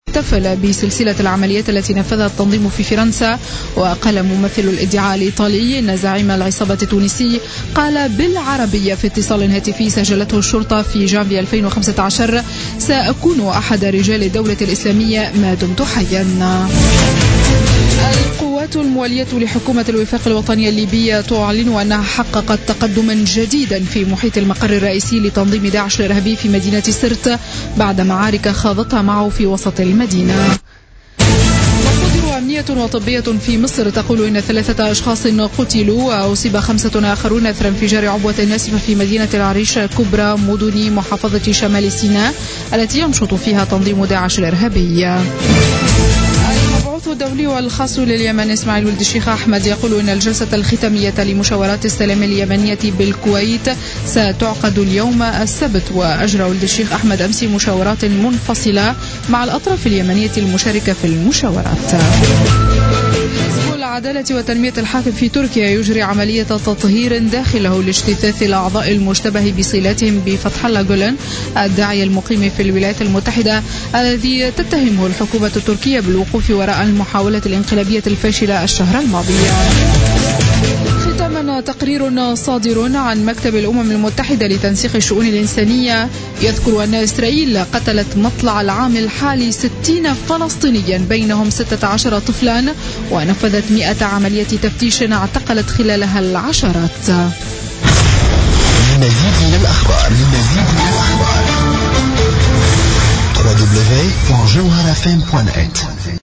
نشرة أخبار منتصف الليل ليوم السبت 6 أوت 2016